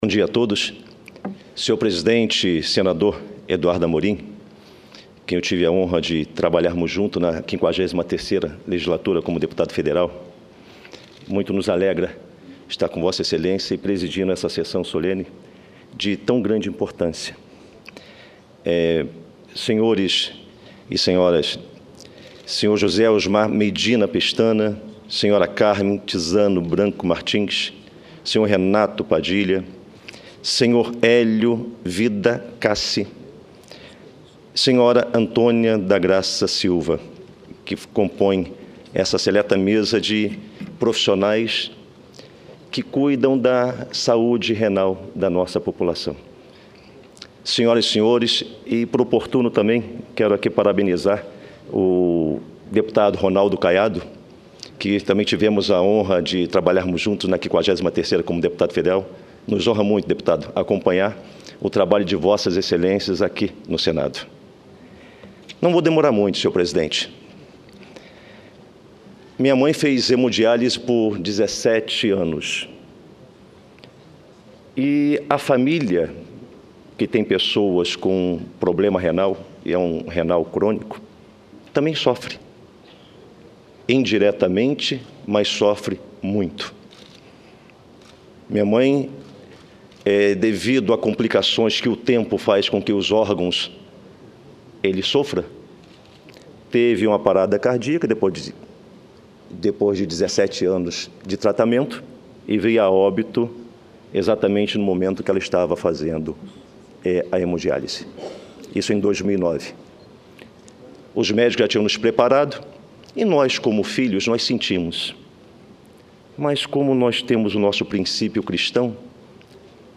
Sessão Especial
Pronunciamento do deputado Vinicius Carvalho